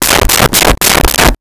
Door Knock 5x
Door Knock 5x.wav